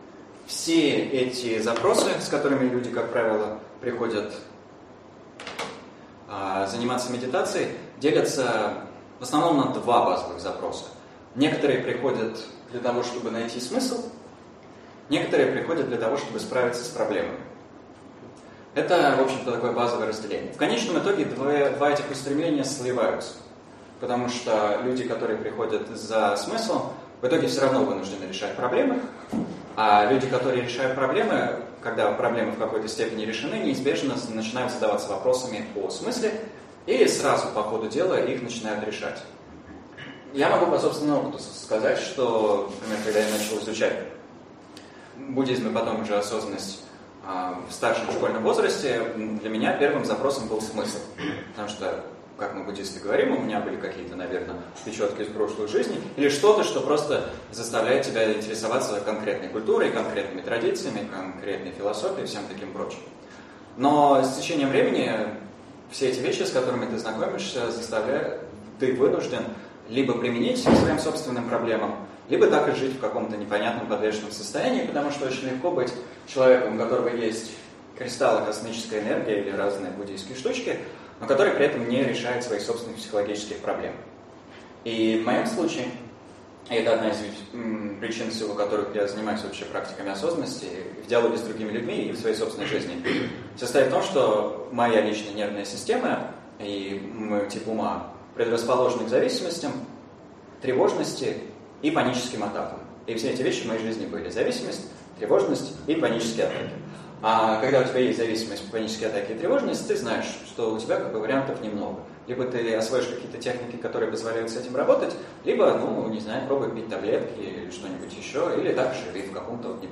Аудиокнига Осознанность и дыхание. Часть 1 | Библиотека аудиокниг